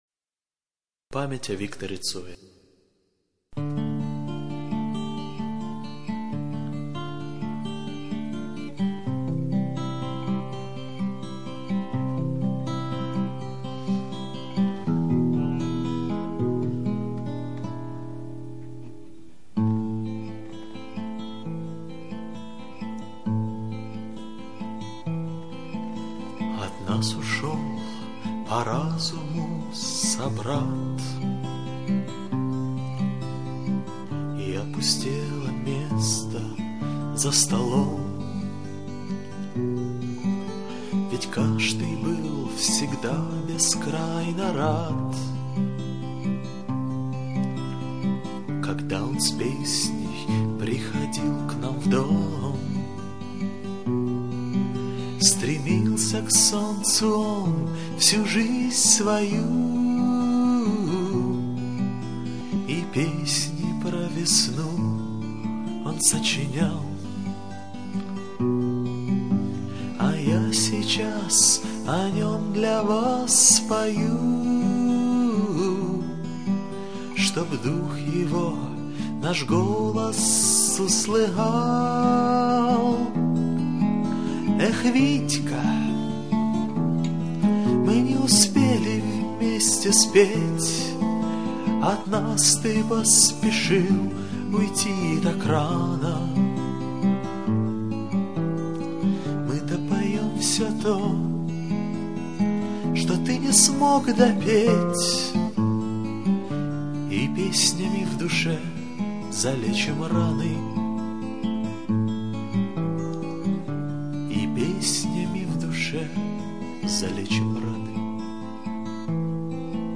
рок-группы